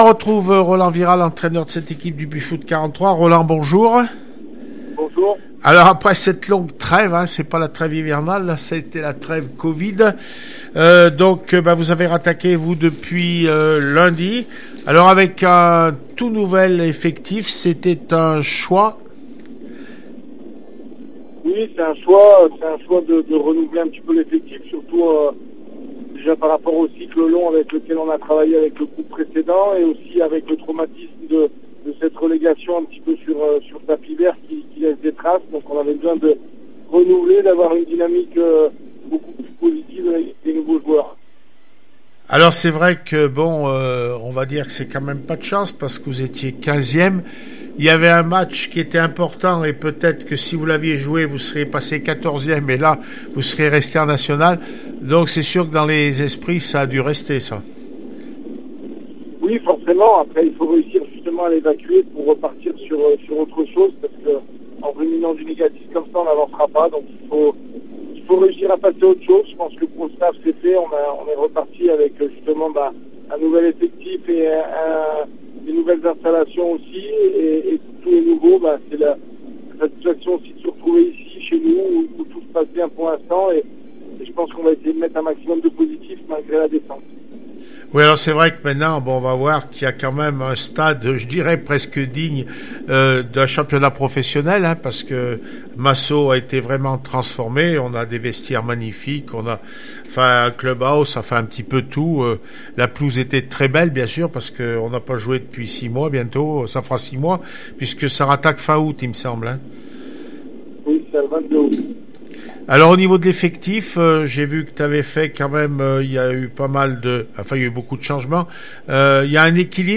17 juillet 2020   1 - Sport, 1 - Vos interviews, 2 - Infos en Bref   No comments